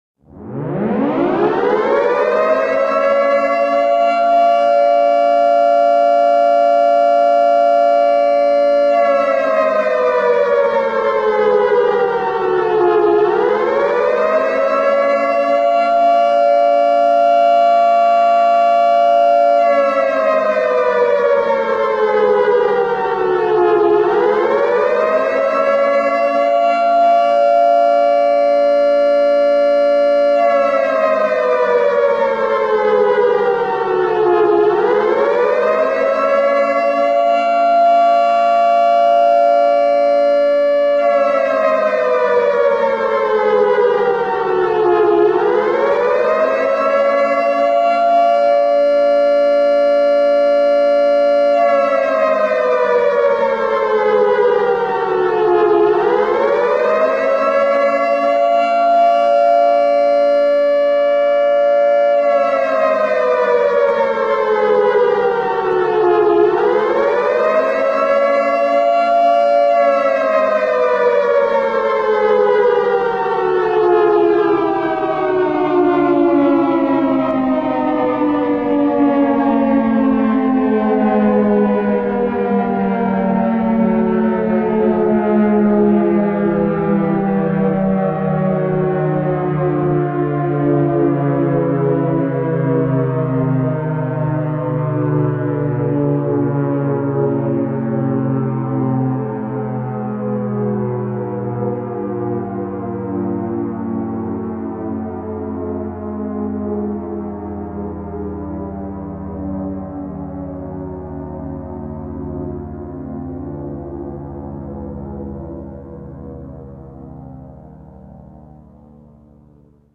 airRaid.ogg